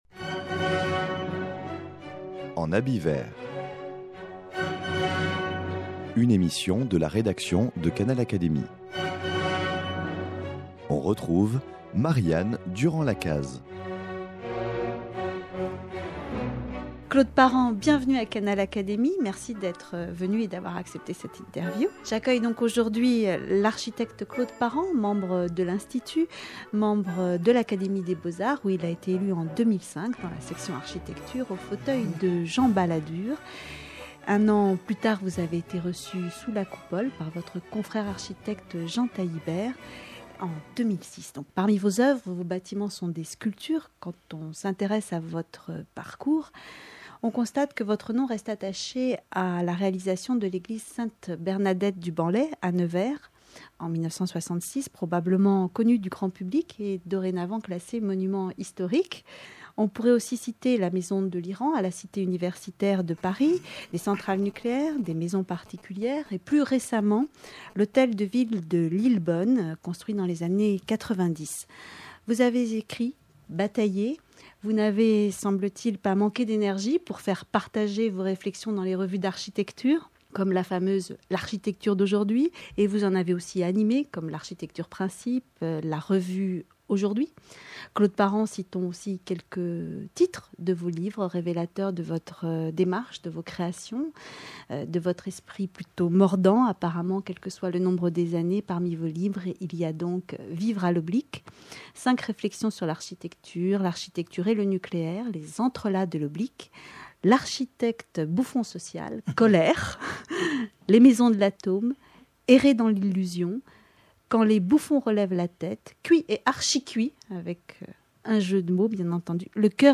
Élu membre de l’Académie des beaux-arts en 2005, au fauteuil de l’architecte Jean Balladur, il raconte d’un humour distancié, son parcours, son engagement passionnel au service de l’architecture et ses rencontres avec les artistes du monde de la peinture et du théâtre.